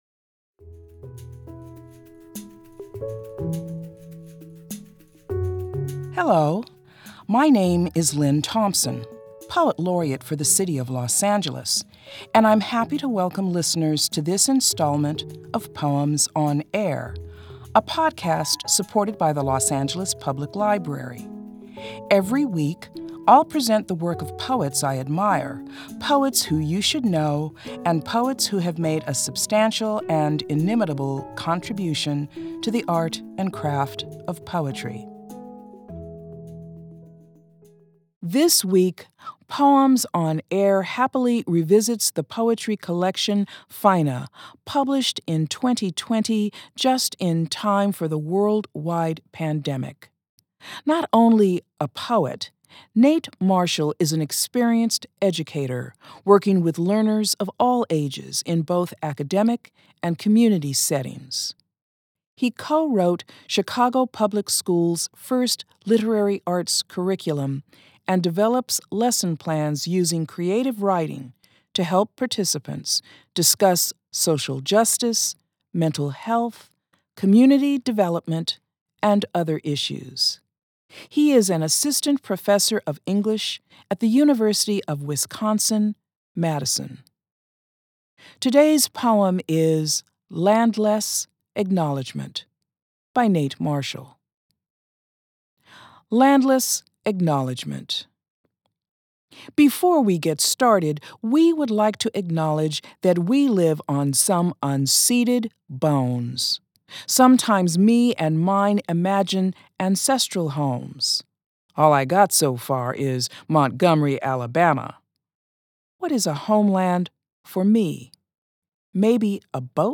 Los Angeles Poet Laureate Lynne Thompson reads "Landless Acknowledgement" by Nate Marshall.